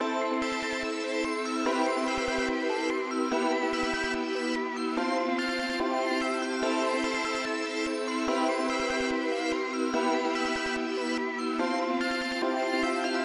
合成器循环
描述：钢琴、琶音和另一个合成器，并带有总的节拍。
标签： 145 bpm Trap Loops Synth Loops 2.23 MB wav Key : Unknown
声道立体声